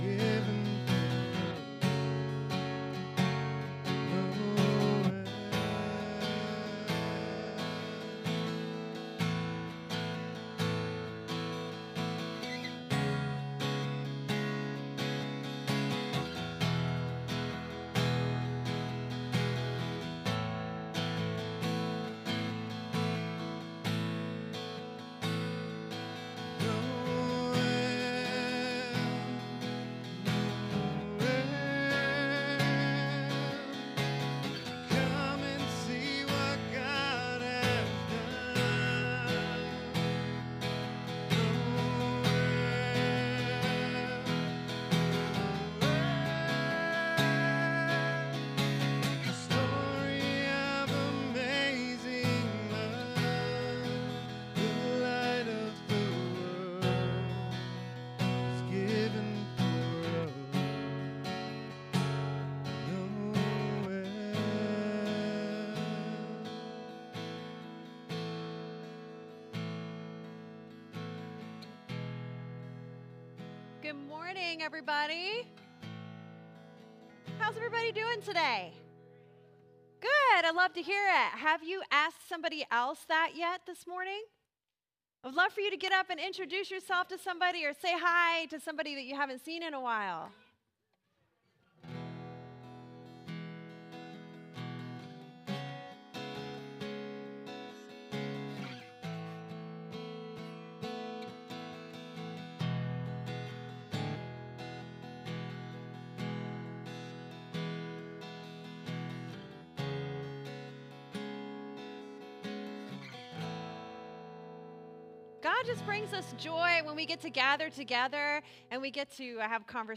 SERMON DESCRIPTION We are highlighting the shepherds’ joy upon hearing the good news of Jesus’ birth.